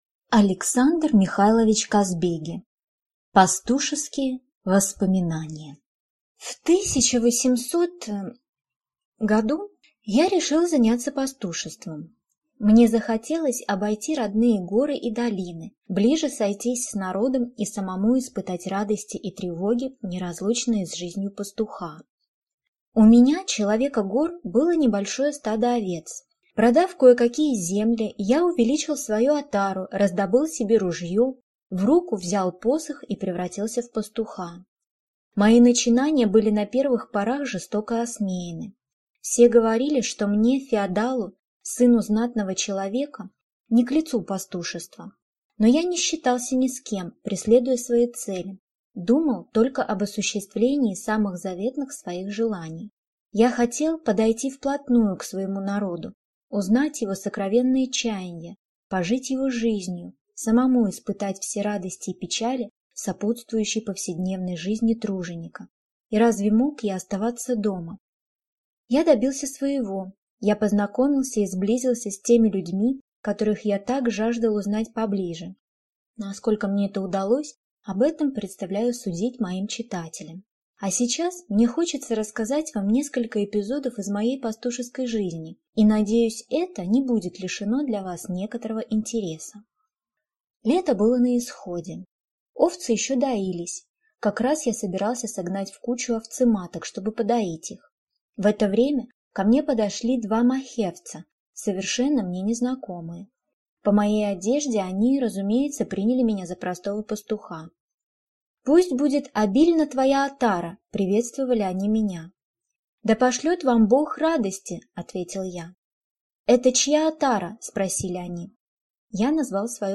Аудиокнига Пастушеские воспоминания | Библиотека аудиокниг